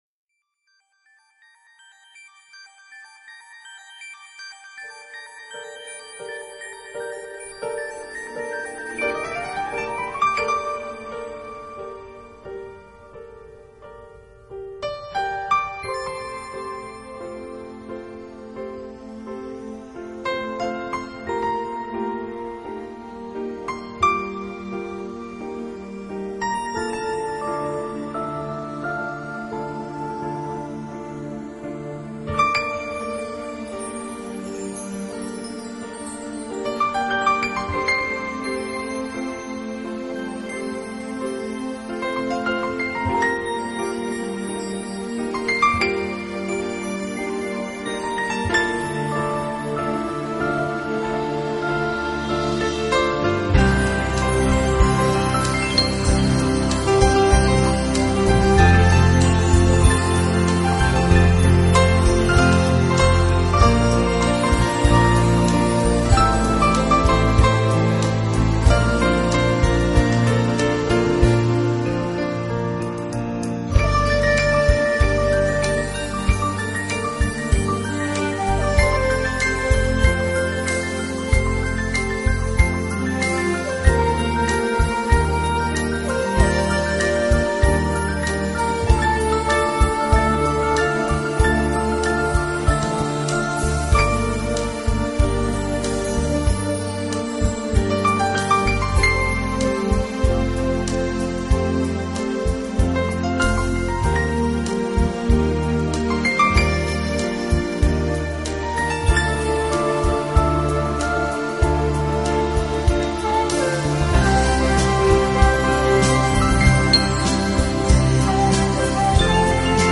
音乐类型:  钢琴